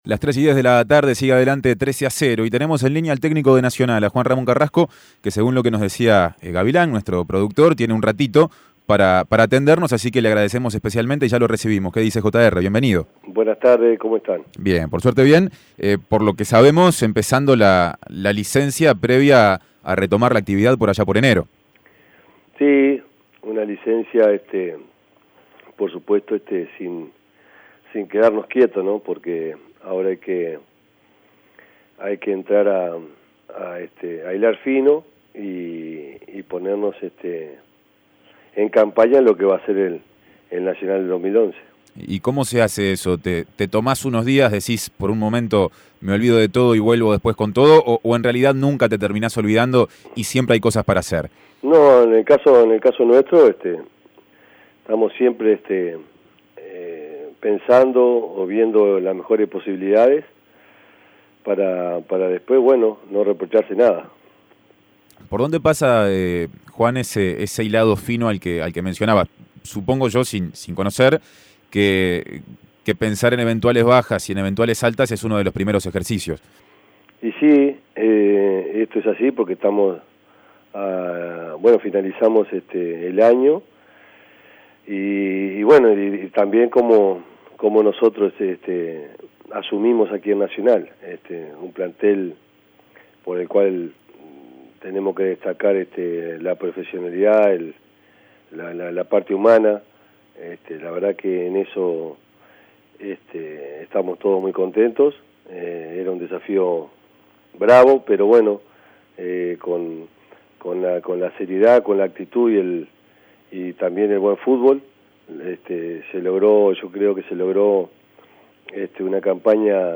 El técnico de Nacional habló con 13 a 0 sobre el campeonato pasado y el Clausura que se viene.